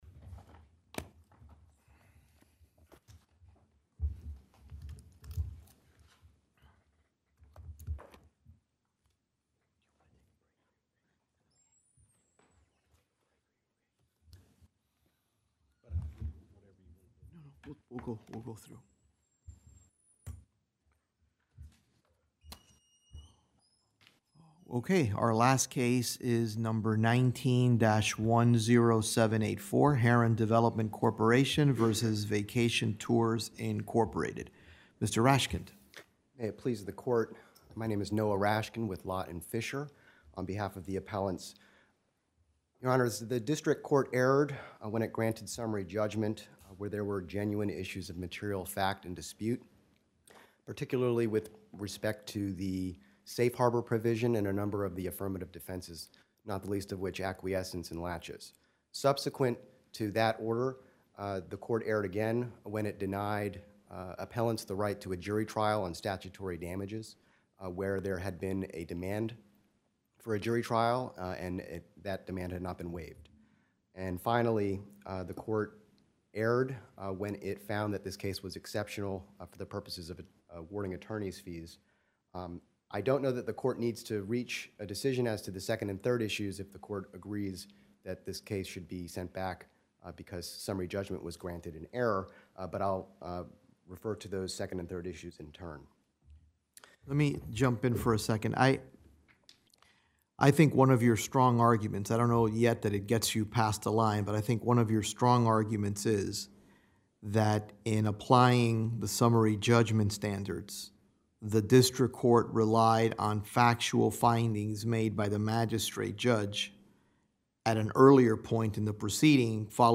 Oral Argument Recordings | United States Court of Appeals